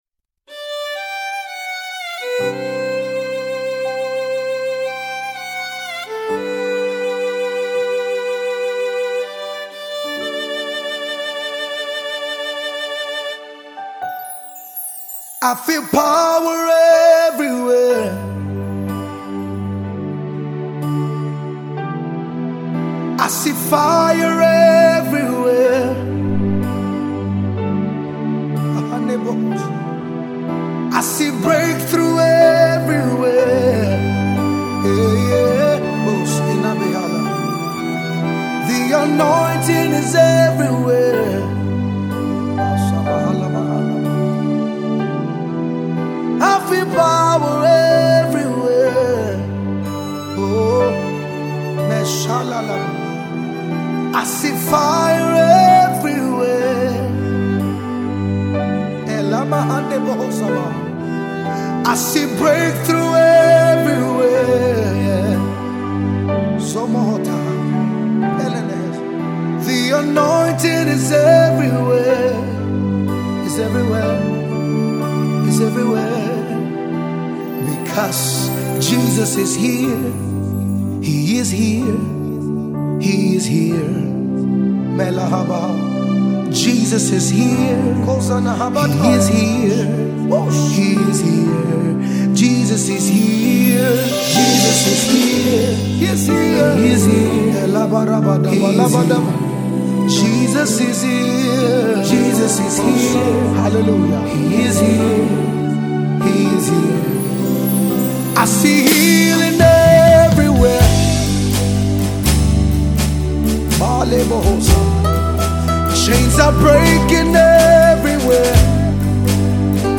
Extraordinary Nigerian based Gospel minister